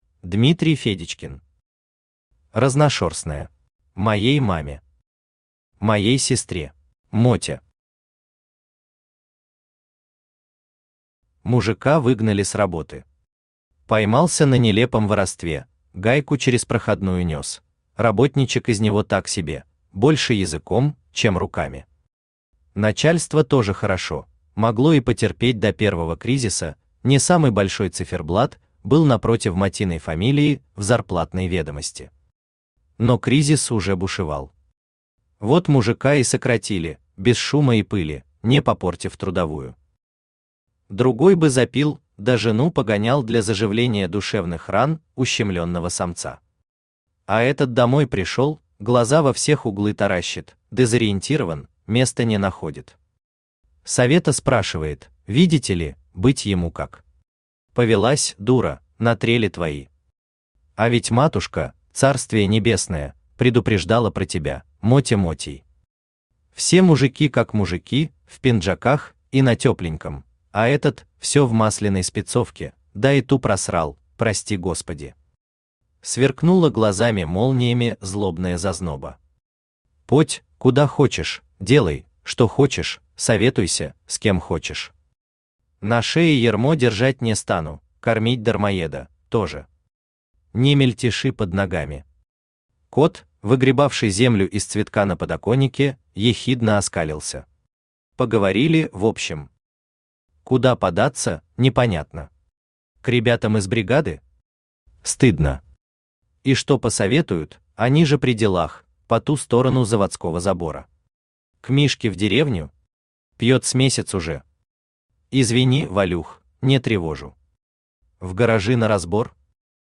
Аудиокнига Разношёрстное | Библиотека аудиокниг
Aудиокнига Разношёрстное Автор Дмитрий Федечкин Читает аудиокнигу Авточтец ЛитРес.